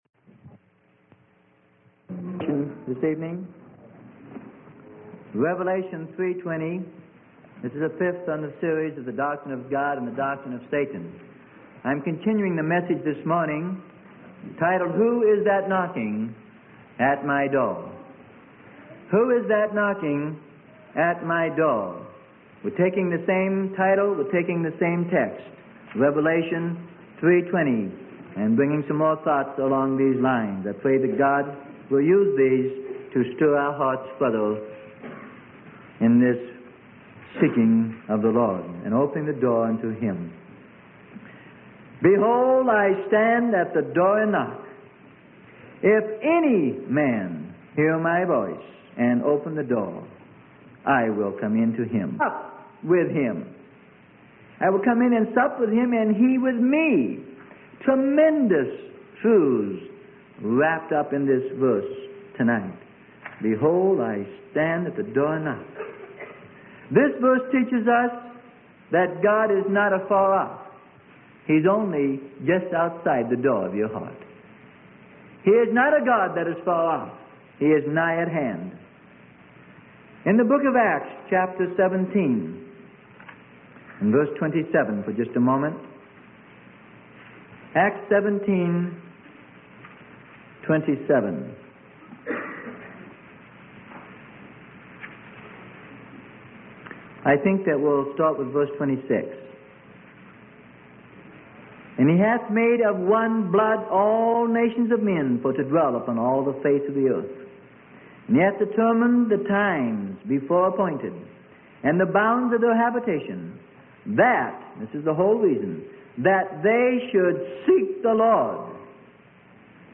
Sermon: God's Doctrine and Satan's Doctrine - Part 5 - Who's That Knocking at My Heart - Freely Given Online Library